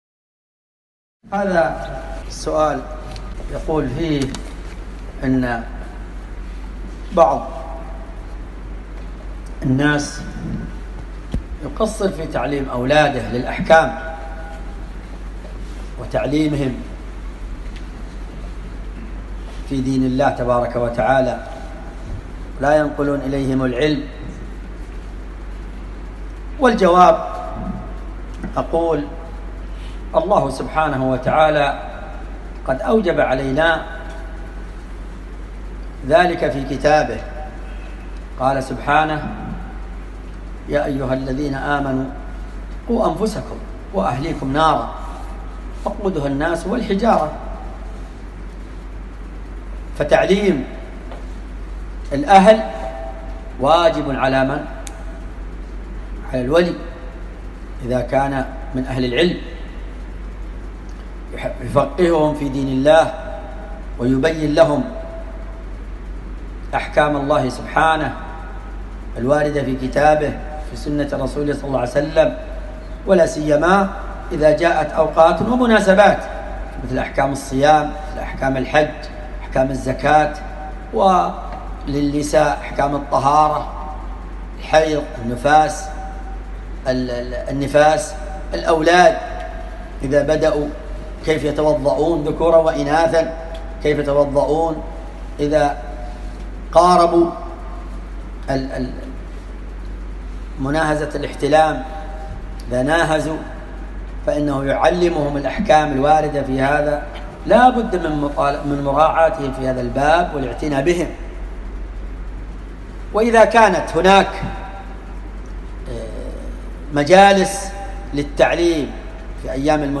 ملف الفتوي الصوتي عدد الملفات المرفوعه : 1
السؤال مقتطف من : ( اللقاء المفتوح في مملكة البحرين يوم الجمعة ٥ شعبان ١٤٣٩ هجري ).